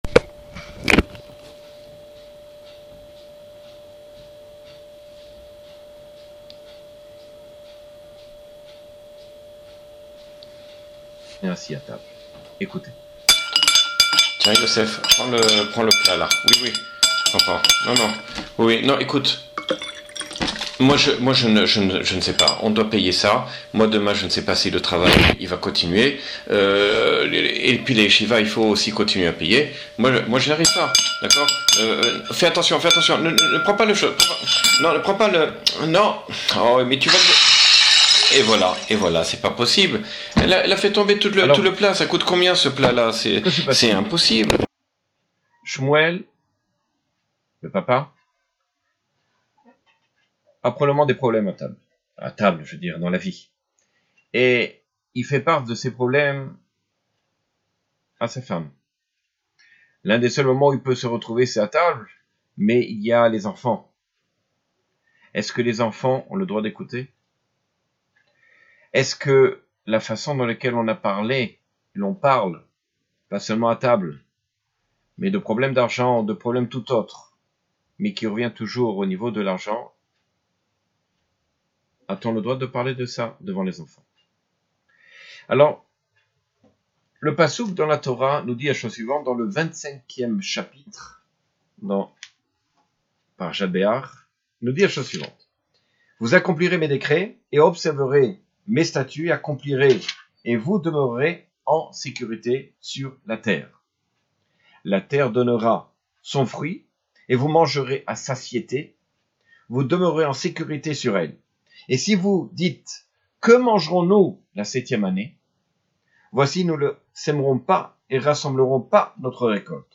Chiour sur l'éducation, Behar 5779 : Parler de Emouna - Beth Haketiva